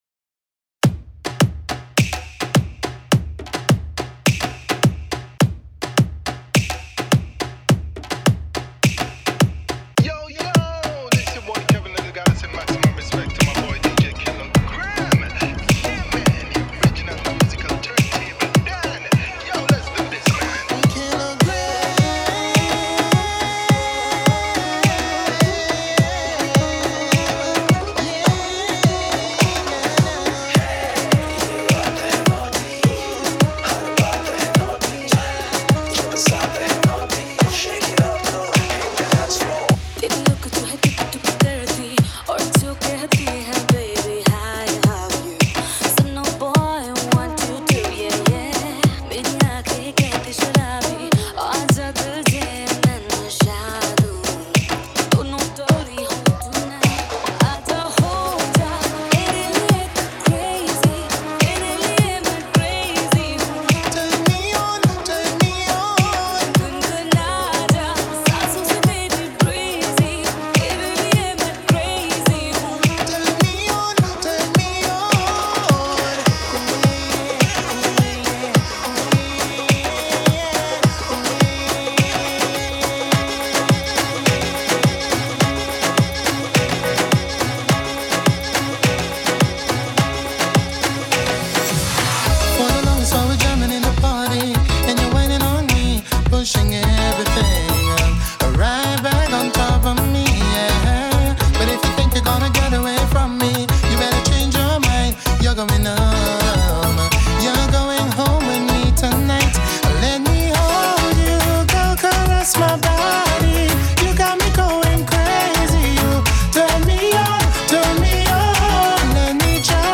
NONSTOP 2021 Latest DJ Remix Songs
Category: NONSTOP